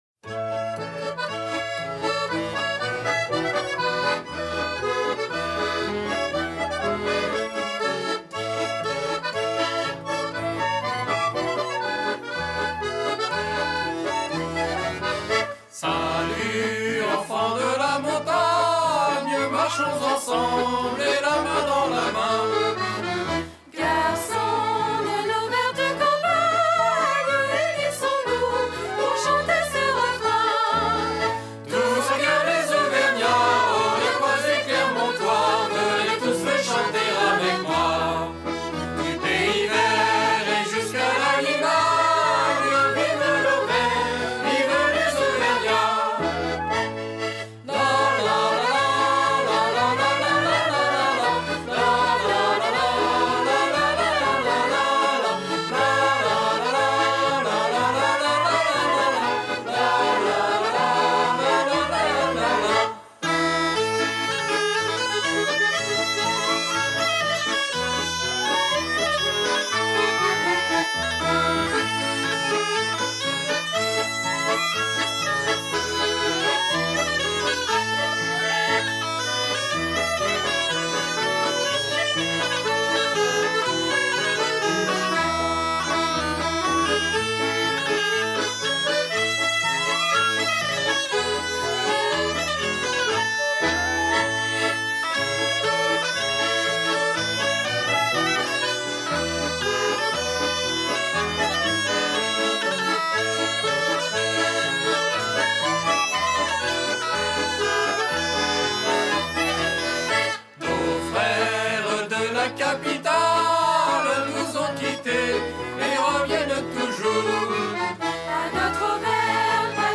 Genre : Musiques du monde.